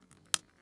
button2.wav